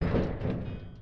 WagonConnectionSFX.wav